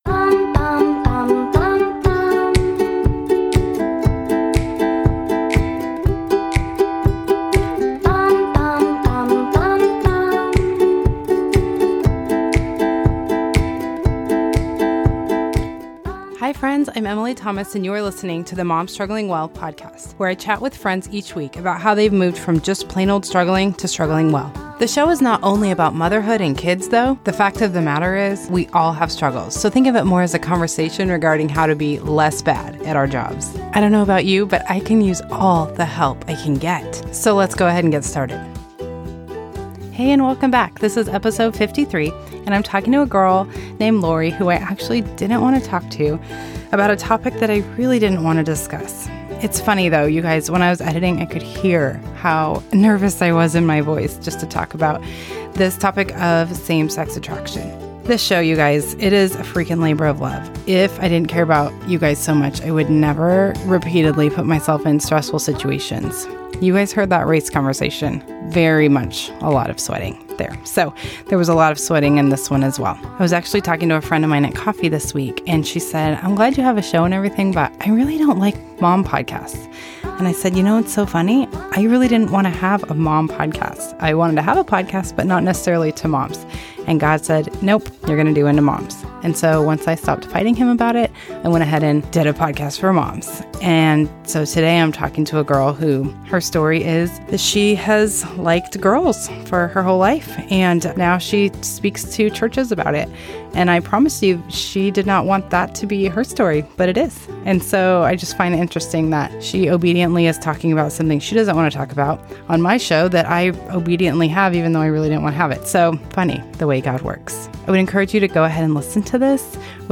This conversation is something the church needs to hear.
She tells her story with gut wrenching honesty but also so much grace.